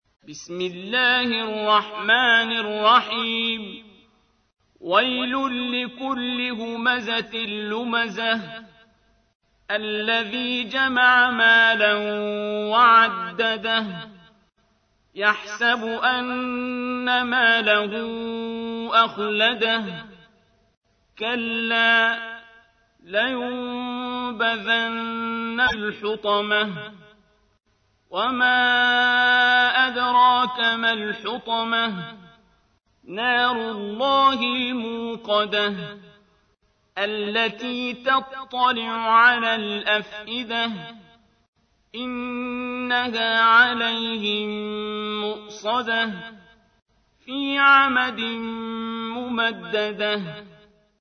تحميل : 104. سورة الهمزة / القارئ عبد الباسط عبد الصمد / القرآن الكريم / موقع يا حسين